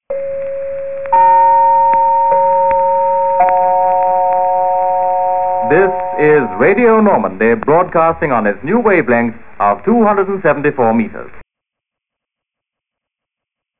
Pour signaler l'intervalle entre les programmes nous nous servions d'un petit xylophone avec son marteau.
sign_on.wav